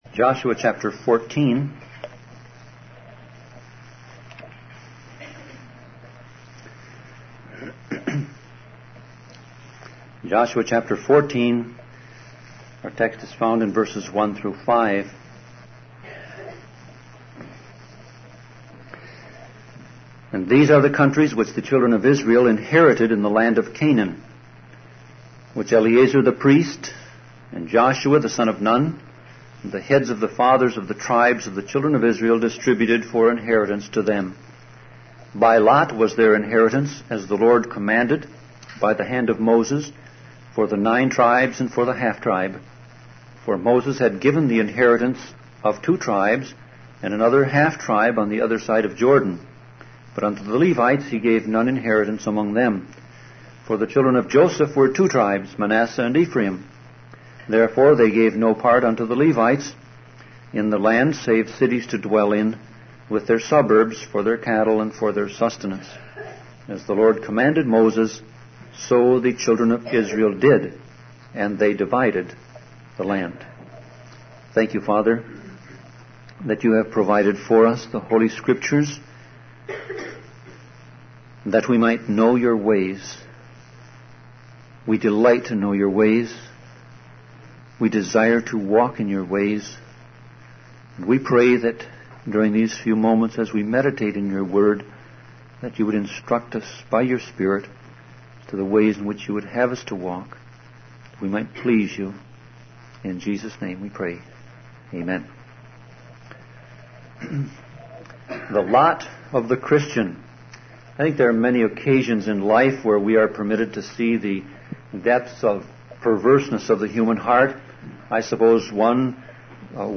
Sermon Audio Passage: Joshua 14:1-5